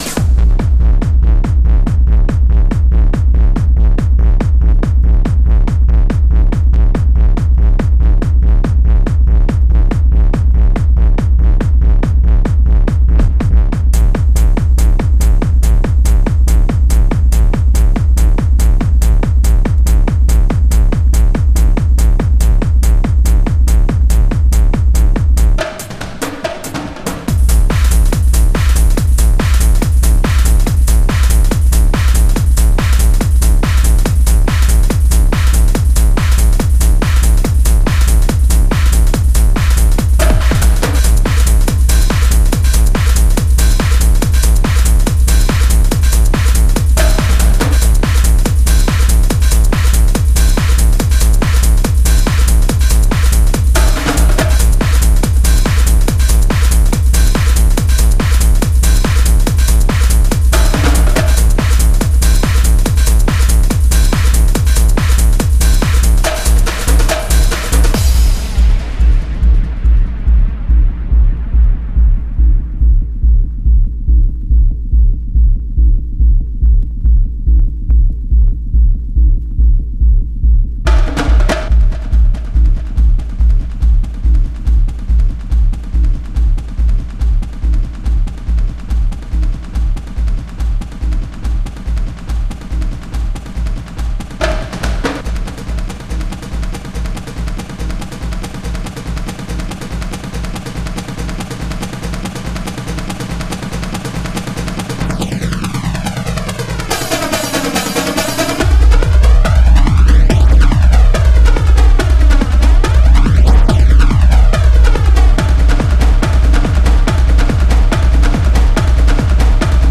Genre: Dance.